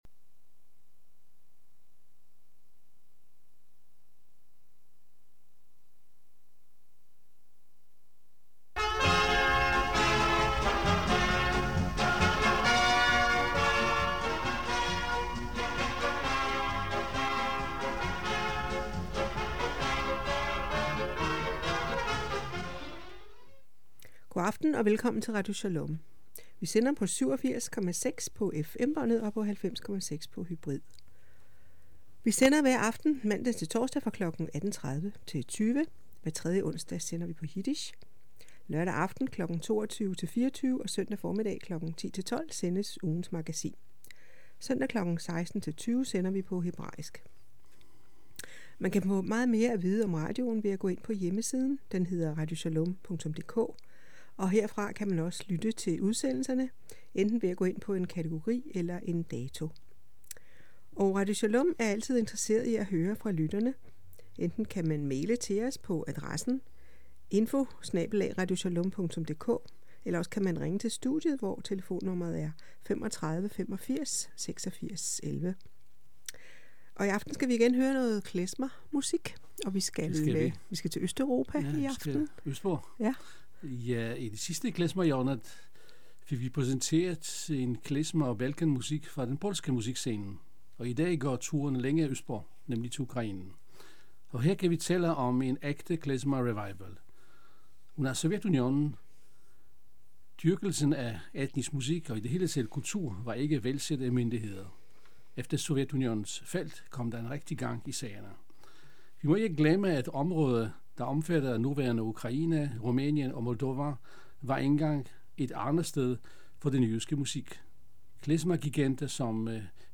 Klezmer hjørne